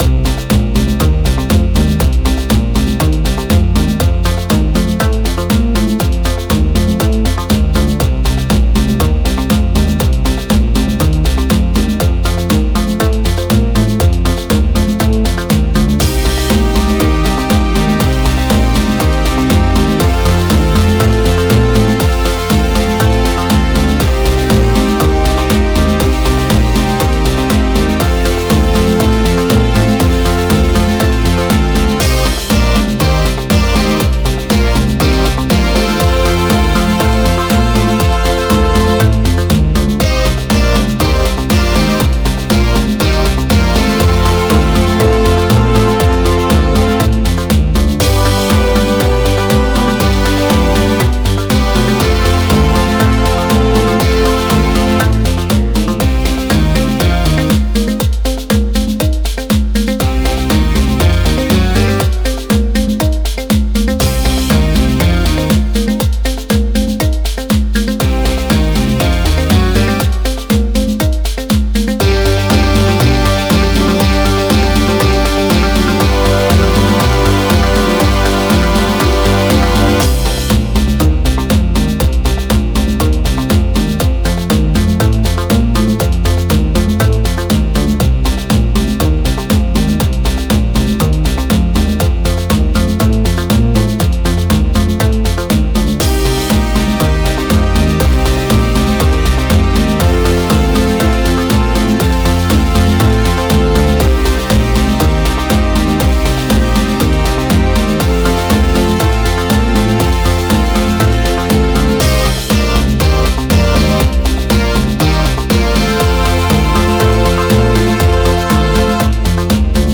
Genre: Electronic, Synthwave.